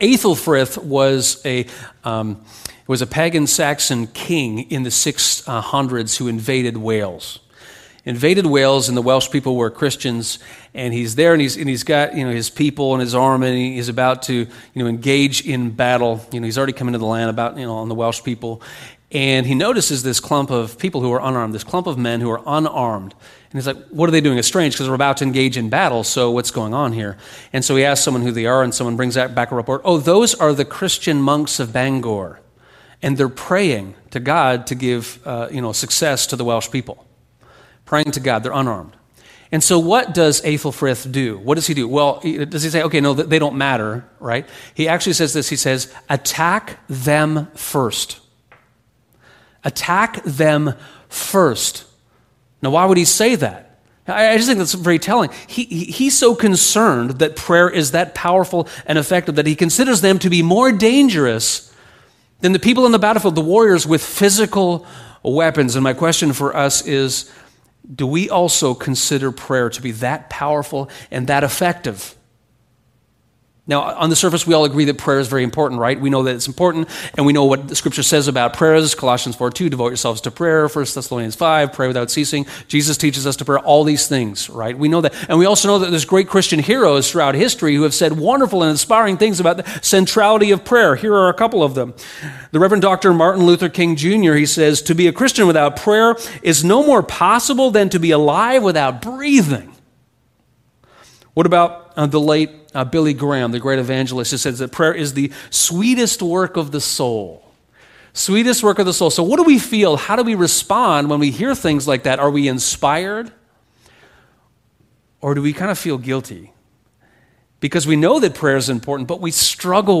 Pray—as a first resort [Sermon]